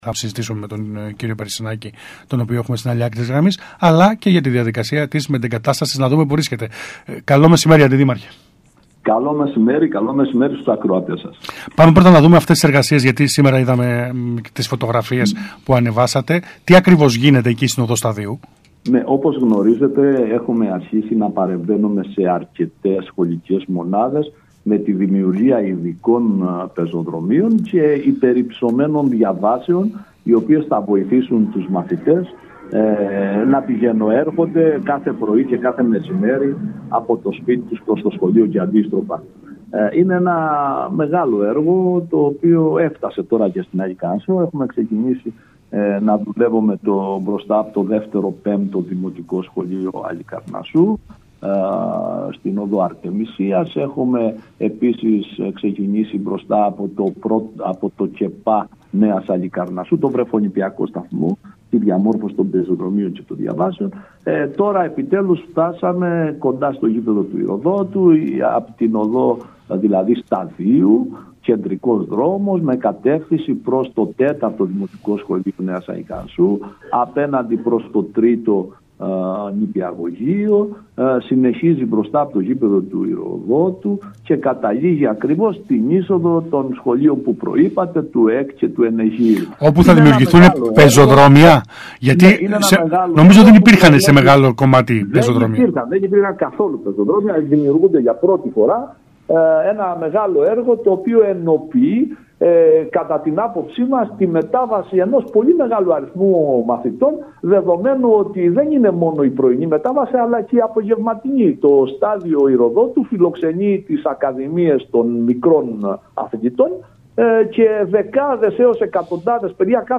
Για το θέμα μίλησε την Τρίτη 21 Οκτωβρίου στον ΣΚΑΙ Κρήτης ο Αντιδήμαρχος Παιδείας Αντώνης Περισυνάκης.